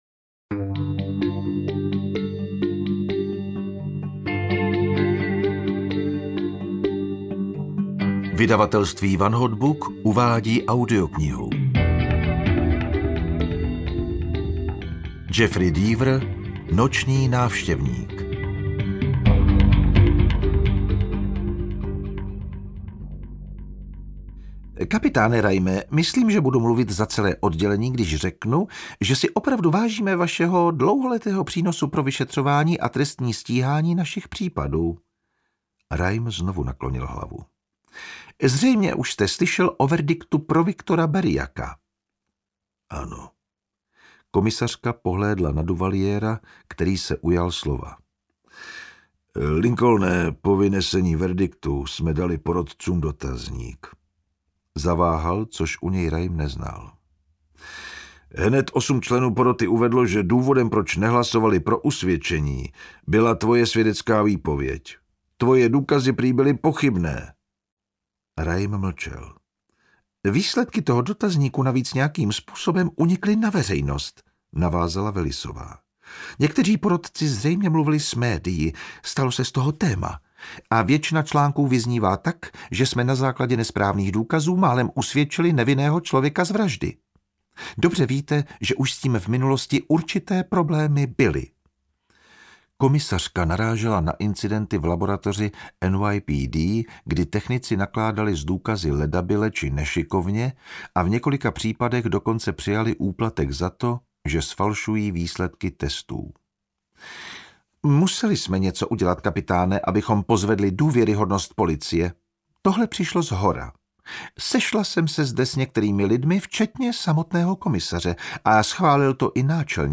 Noční návštěvník audiokniha
Ukázka z knihy
• InterpretJan Vondráček